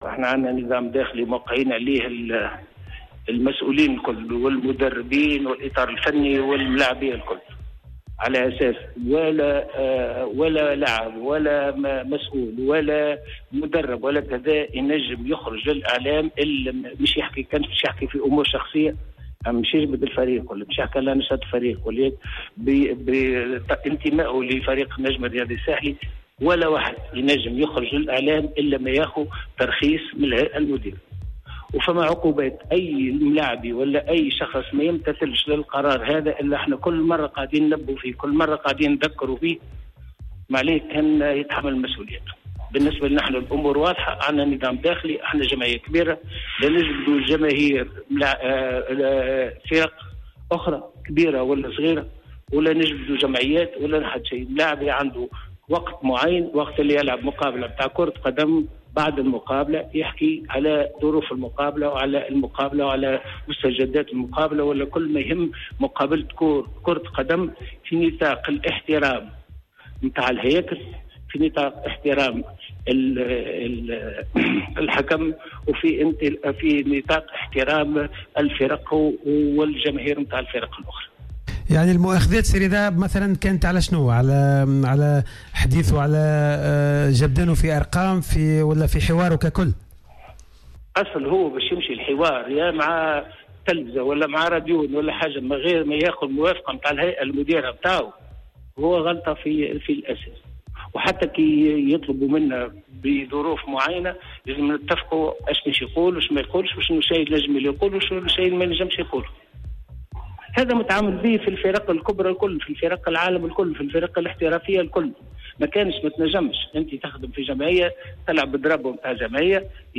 تطرق رئيس النجم الرياضي الساحلي، الدكتور رضا شرف الدين اليوم الثلاثاء خلال حواره في برنامج "cartes sur table" على راديو " الجوهرة اف ام " عن التصريحات الاذاعية التي ادلى بها لاعب الفريق ايهاب المساكني مؤخرا، والتي كشف خلالها عن العديد من المسائل المتعلقة بالنادي.